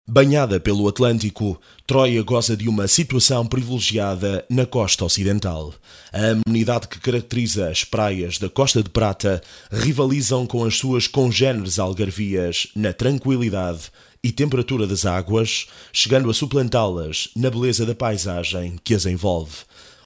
Energetic and fresh Portuguese Voice with different registers.
Sprechprobe: Werbung (Muttersprache):
Professional Portuguese VO talent.